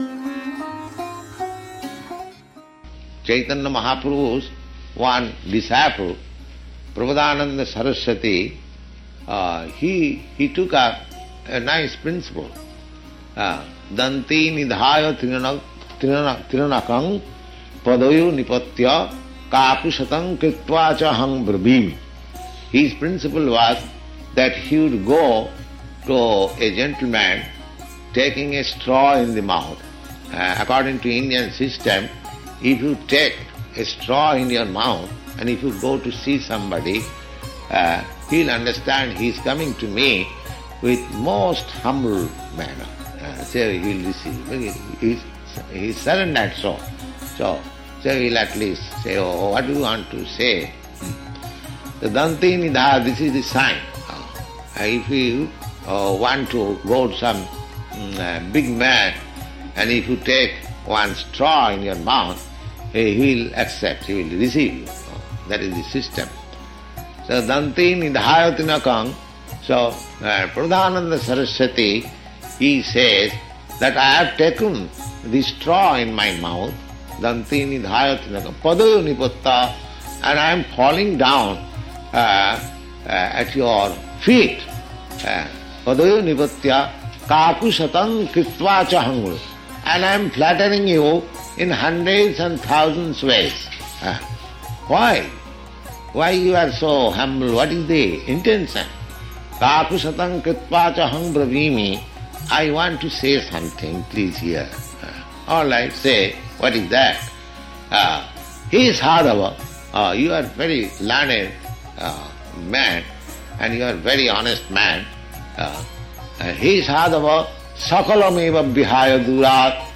(670123 – Lecture CC Madhya 25.36-40 – San Francisco)